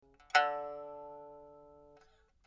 pipa4.mp3